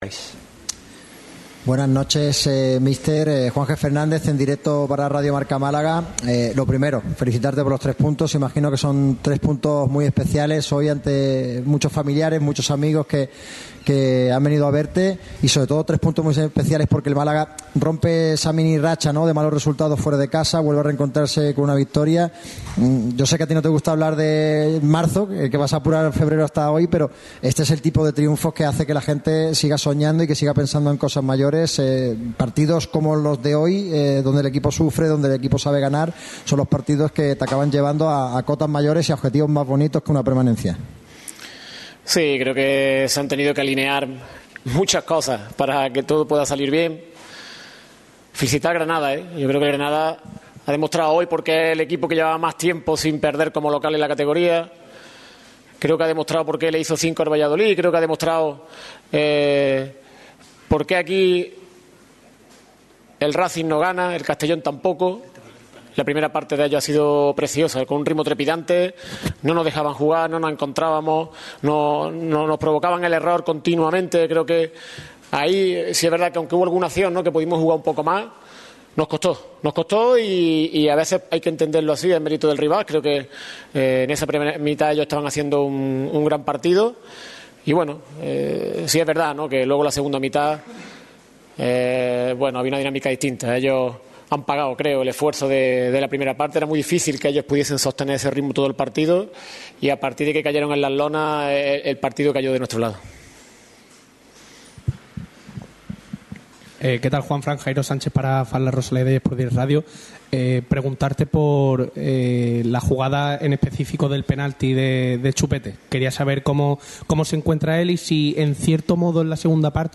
Rueda de prensa íntegra